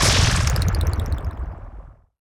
explosionBlack.wav